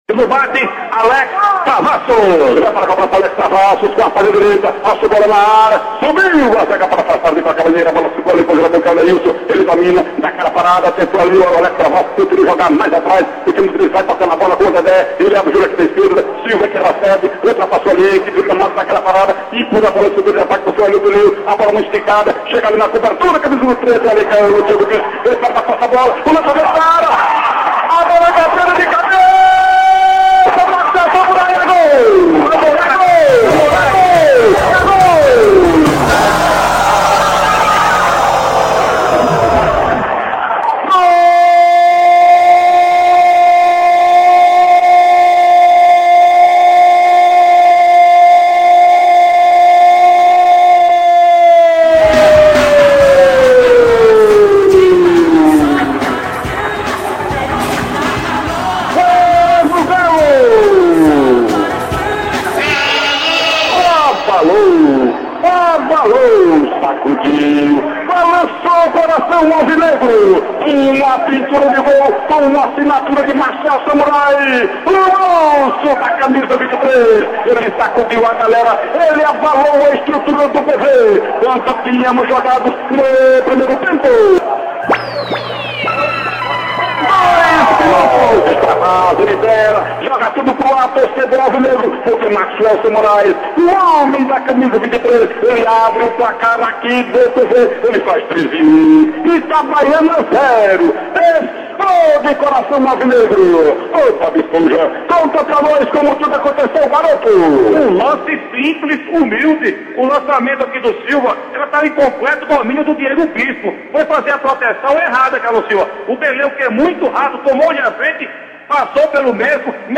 gol-do-Treze-x-Ita-online-audio-converter.com_.mp3